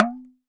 Index of /musicradar/essential-drumkit-samples/Hand Drums Kit
Hand Talking Drum 01.wav